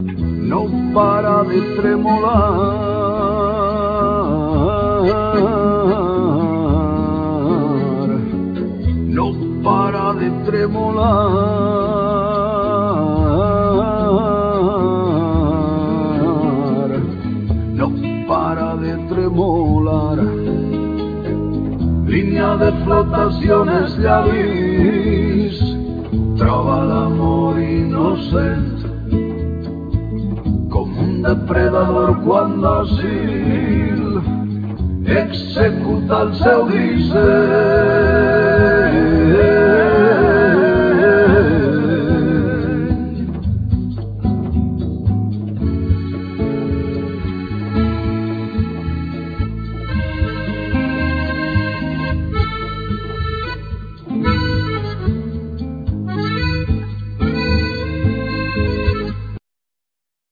Vocal,Percussions
Dolcaina,Gralla,Cromorn,Gaida bulgara i xirimia